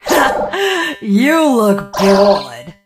jackie_drill_kill_vo_05.ogg